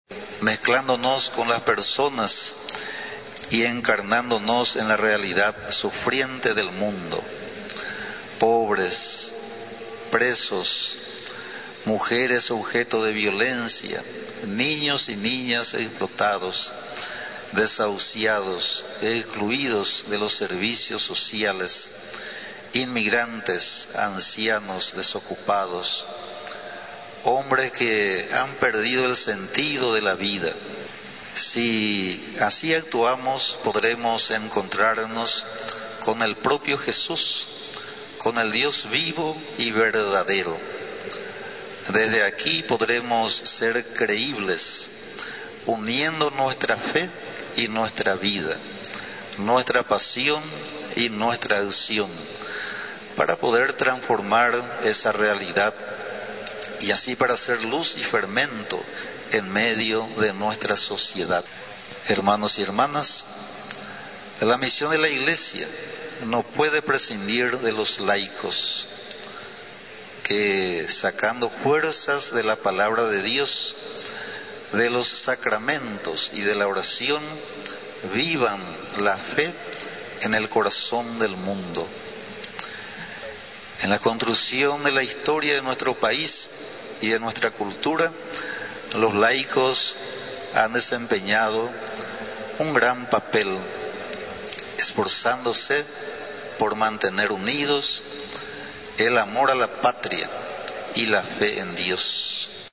La sociedad debe dejar de ser indiferente a las necesidades de los demás, afirmó este lunes en el segundo día del novenario a la Virgen de Caacupé, el obispo de Misiones y Ñeembucú, monseñor Pedro Collar.